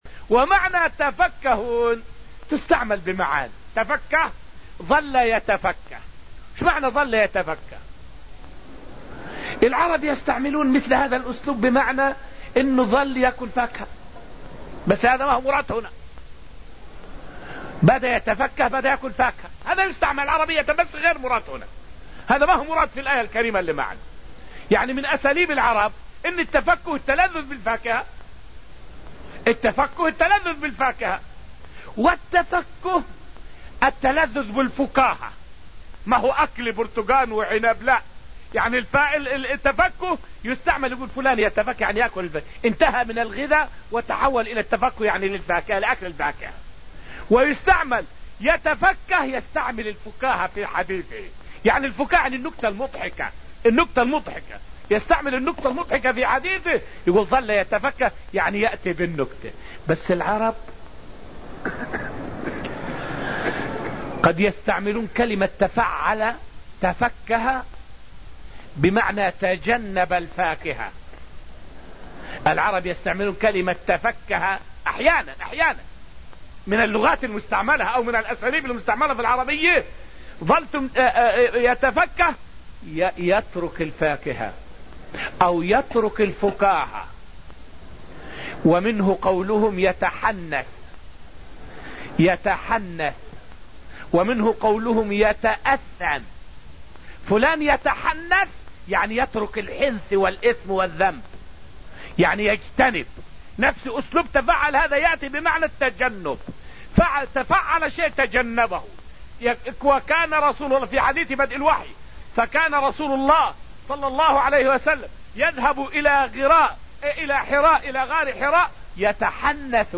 فائدة من الدرس السادس من دروس تفسير سورة الواقعة والتي ألقيت في المسجد النبوي الشريف حول معجزة فوران الماء بين يدي النبي صلى الله عليه وسلم.